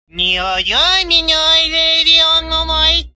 Category: Funny Ringtones